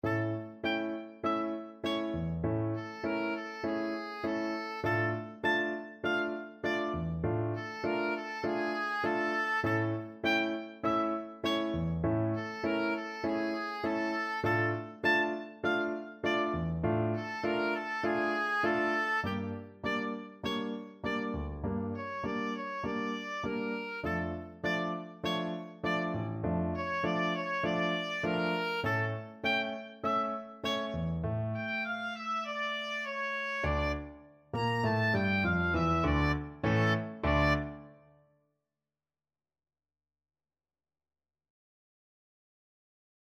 Oboe version
D minor (Sounding Pitch) (View more D minor Music for Oboe )
Tempo di Tango
G5-Bb6
4/4 (View more 4/4 Music)